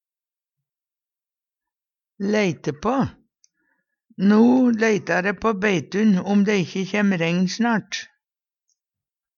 leite på - Numedalsmål (en-US)